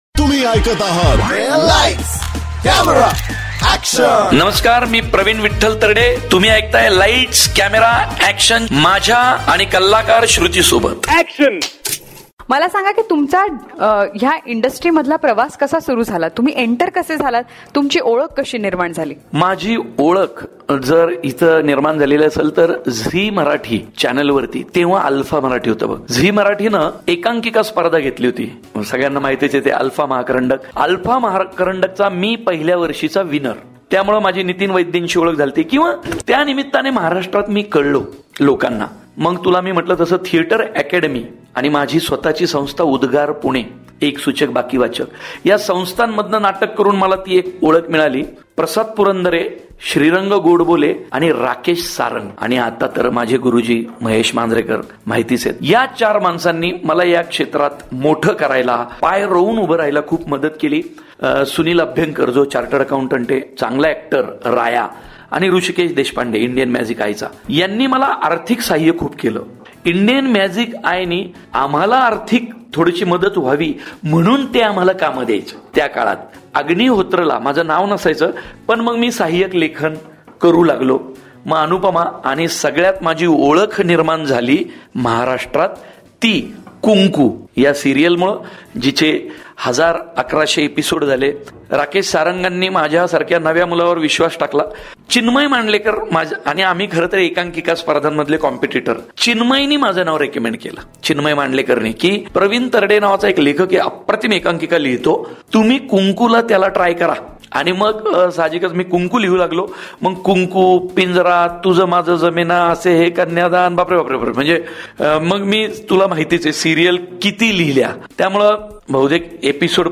CONVERSATION WITH PRAVIN TARDE PART 4